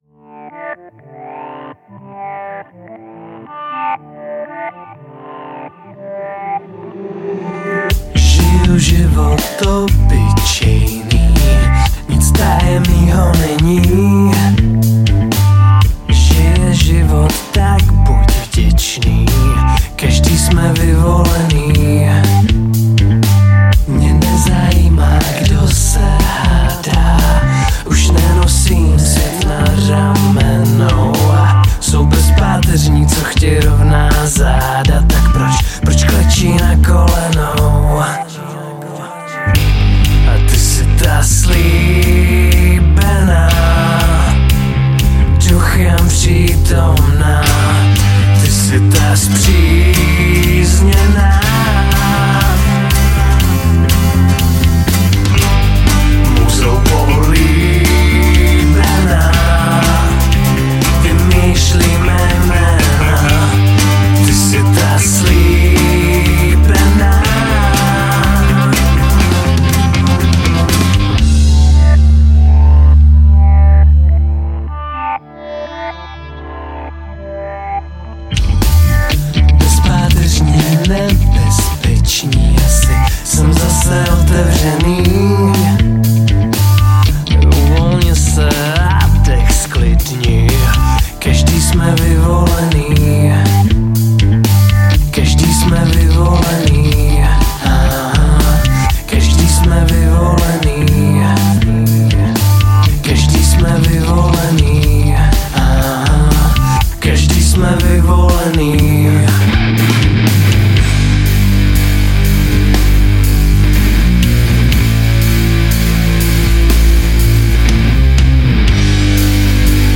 Žánr: Indie/Alternativa
baskytara